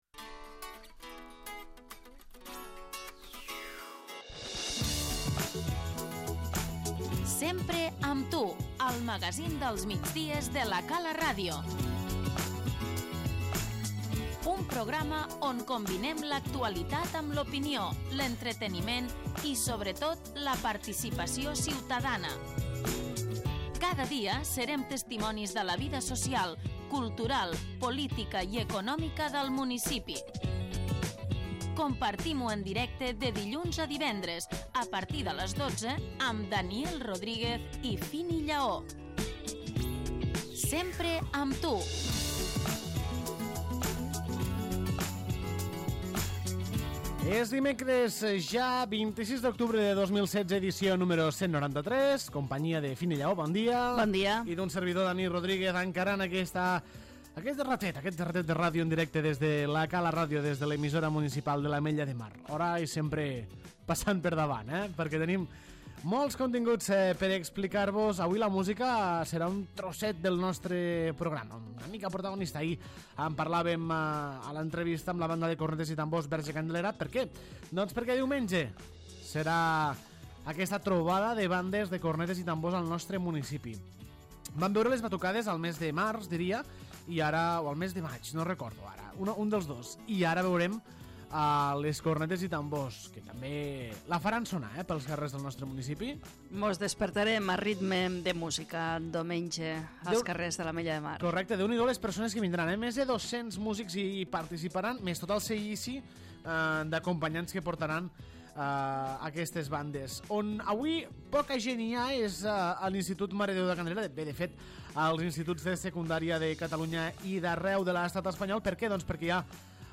En aquest nou episodi del magazín dels migdies de La Cala Ràdio, corresponent al 193 del Sempre amb tu, ha estat notícia:
L'ENTREVISTA El terror s'apodera de la població aquest cap de setmana, almenys a la Sala de la Societat.